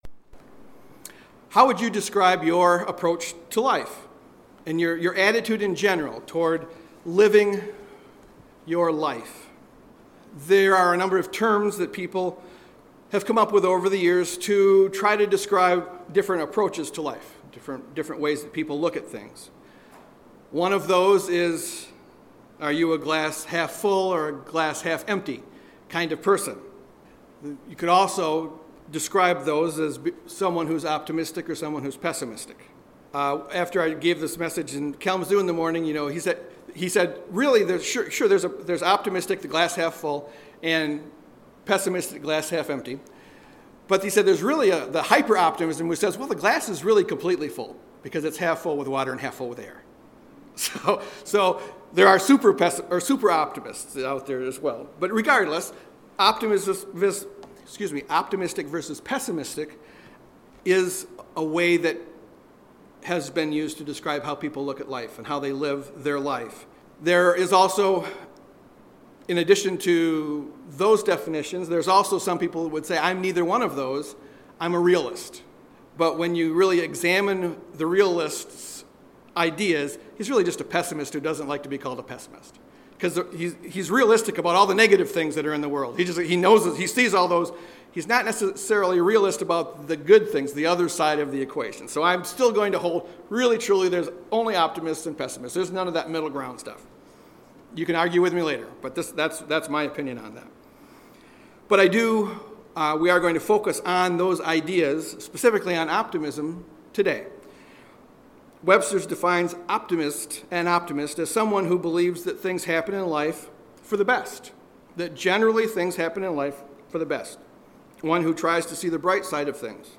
Sermons
Given in Grand Rapids, MI